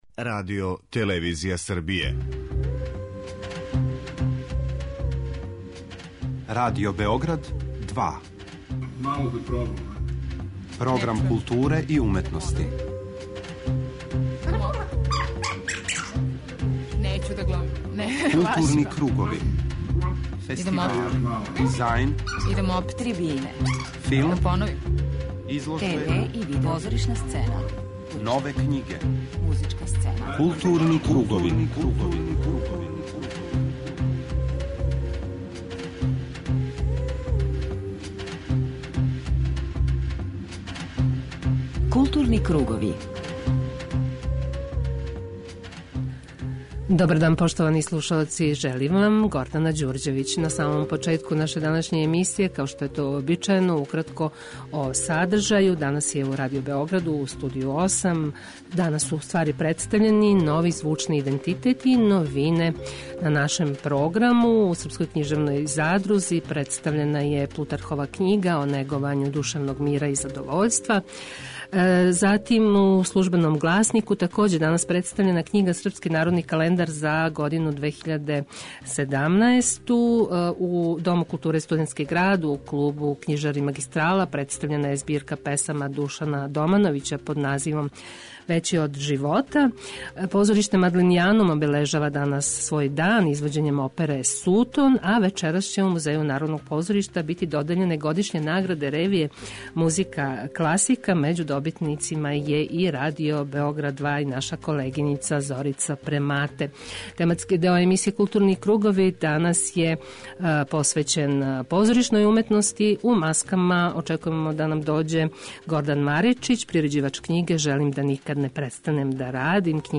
преузми : 41.18 MB Културни кругови Autor: Група аутора Централна културно-уметничка емисија Радио Београда 2.